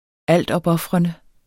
Udtale [ ˈalˀdʌbˌʌfʁʌnə ]